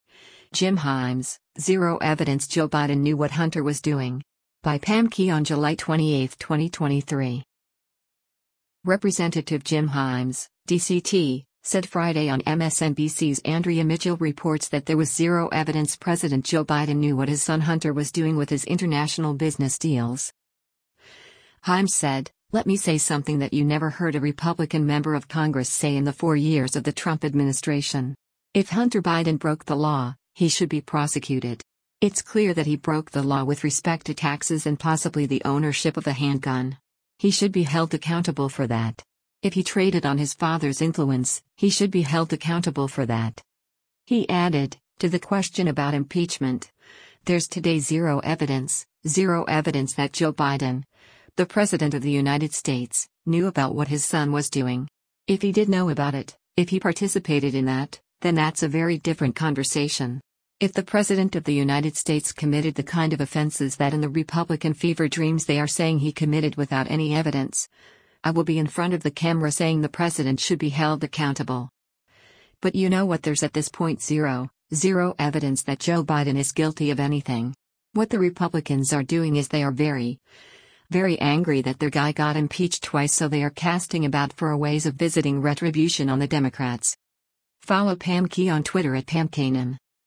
Representative Jim Himes (D-CT) said Friday on MSNBC’s “Andrea Mitchell Reports” that there was “zero evidence” President Joe Biden knew what his son Hunter was doing with his international business deals.